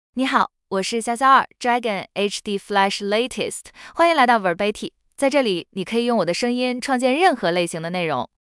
Xiaoxiao2 Dragon HDFlash LatestFemale Chinese AI voice
Xiaoxiao2 Dragon HDFlash Latest is a female AI voice for Chinese (Mandarin, Simplified).
Voice sample
Listen to Xiaoxiao2 Dragon HDFlash Latest's female Chinese voice.
Female